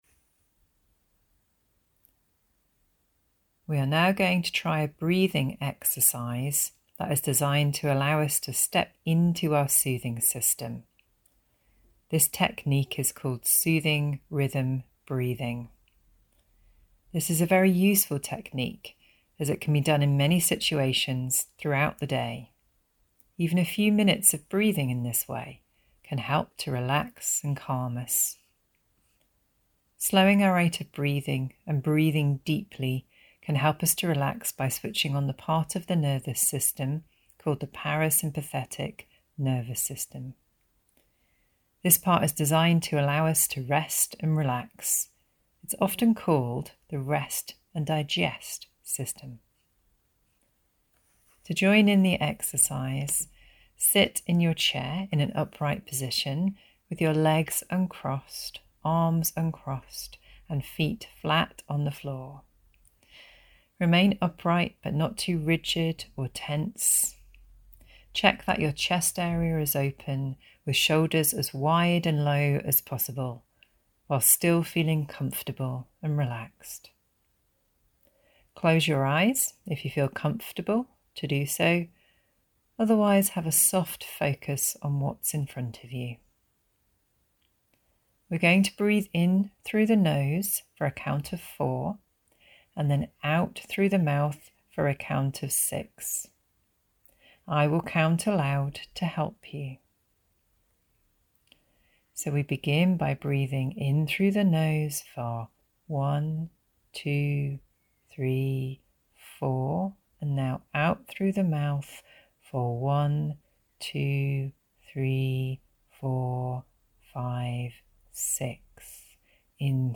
Una cosa che possiamo fare è cambiare consciamente questo stato mentale e attivare il sistema di “calma” imparando a respirare in un modo che calmi il sistema nervoso: conosciuta come “respirazione quadrata” (o soothing rhythm breathing). Prova questa respirazione calmante ascoltando